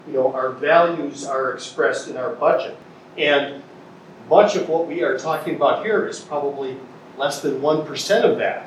Major Dave Anderson says these priorities are what they will pursue after they have made sure that they have funded all other critical city operations like maintaining the infrastructure, making sure the plumbing works and providing police and fire protection, among others.